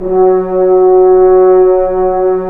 Index of /90_sSampleCDs/Roland L-CD702/VOL-2/BRS_F.Horns 1/BRS_FHns Ambient
BRS F.HRNS06.wav